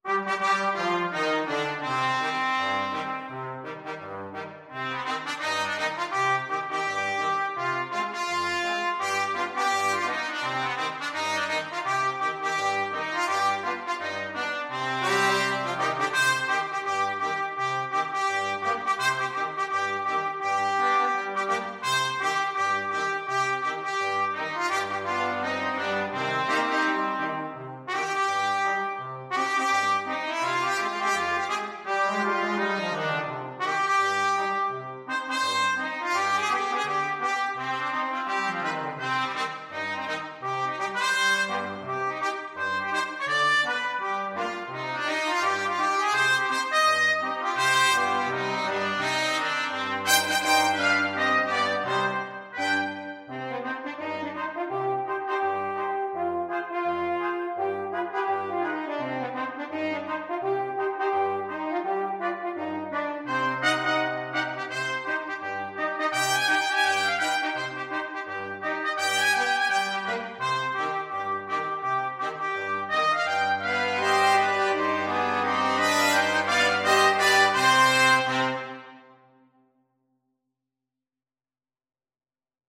Trumpet 1Trumpet 2French HornTrombone
2/2 (View more 2/2 Music)
Quick Swing = 84
Rock and pop (View more Rock and pop Brass Quartet Music)